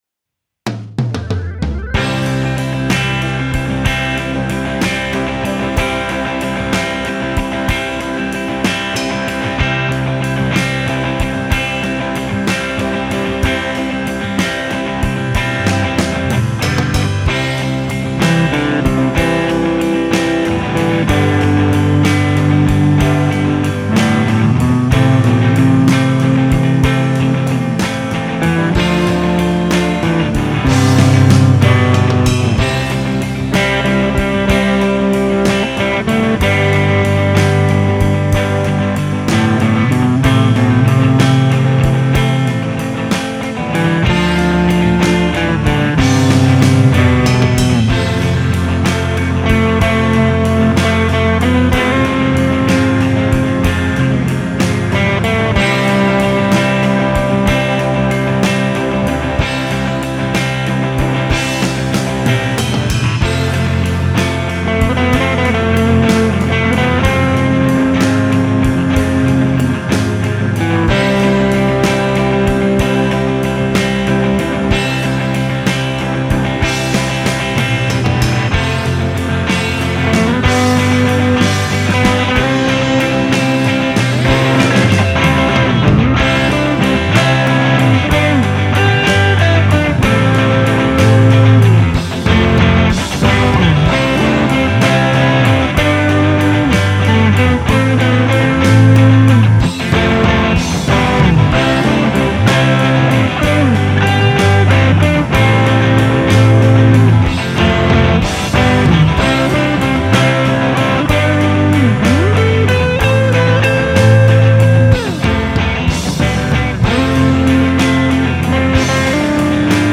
VOX MV50です。
そんでもって早速、レコって見ました! toped liveのキャビシミュ使いました。フェンダー使いました。 バッキングとメロディー弾いてみました。